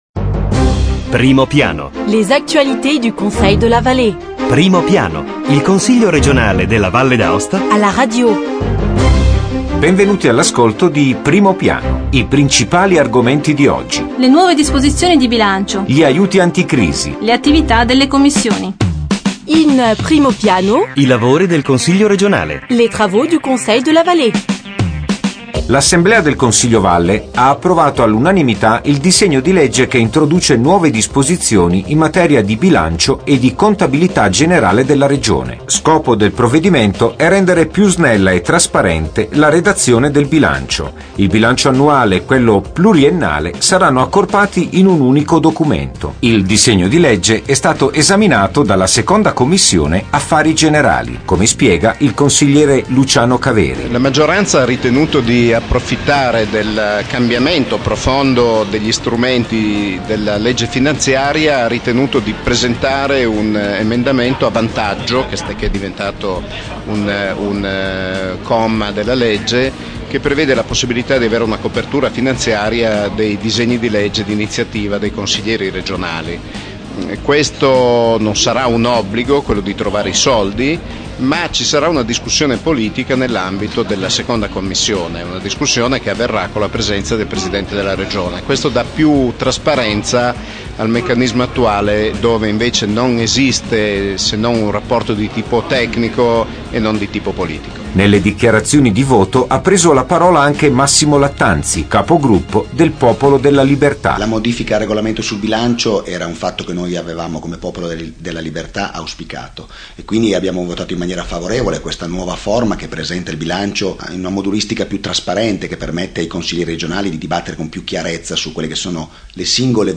Evénements et anniversaires Documents liés 15 septembre 2009 Primo piano Le Conseil r�gional � la radio: approfondissement hebdomadaire sur l'activit� politique, institutionnelle et culturelle de l'assembl�e l�gislative. Voici les th�mes de la nouvelle transmission: les nouvelles dispositions en mati�re de budget et de comptabilit� g�n�rale de la R�gion , avec les interviews � Luciano Caveri, membre de la II Commission � Affaires g�n�rales � et � Massimo Lattanzi, Conseiller Chef du groupe Il Popolo della Libert�; les aides temporaires aux entreprises pour la lutte contre la crise; la reprise des travaux des Commissions permanentes.